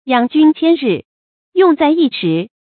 注音：ㄧㄤˇ ㄐㄩㄣ ㄑㄧㄢ ㄖㄧˋ ，ㄩㄥˋ ㄗㄞˋ ㄧ ㄕㄧˊ